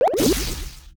fire_loss.wav